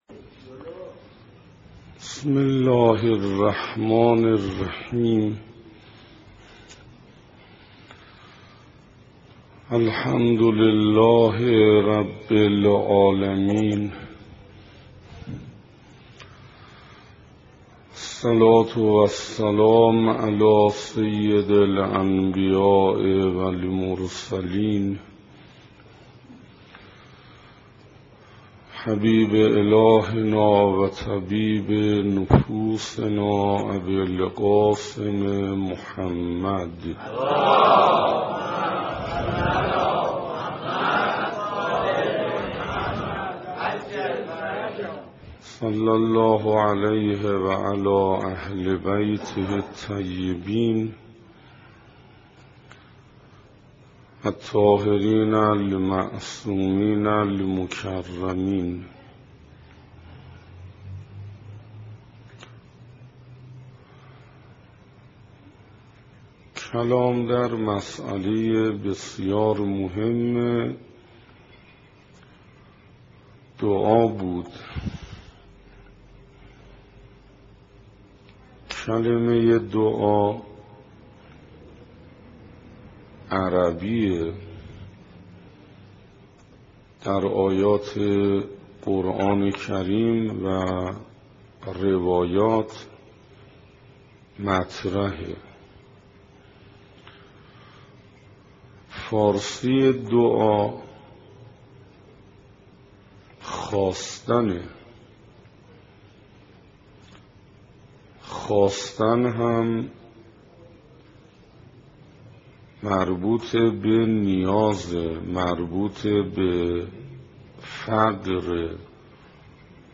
سخنراني پنجم
صفحه اصلی فهرست سخنرانی ها نگاهي به آيات قرآن (2) سخنراني پنجم (تهران بیت الزهرا (س)) رمضان1427 ه.ق - مهر1385 ه.ش دانلود متاسفم..